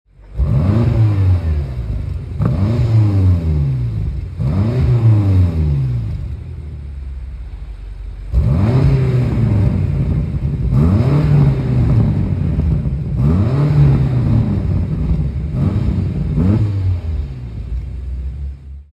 • 4.4 M-Power Twin-Turbo V8 (Petrol) Engine
Listen to this V8 Roar!
• M Sports Exhaust System with Black Tailpipes
BMW-M5-Competition-Blue-REVS.mp3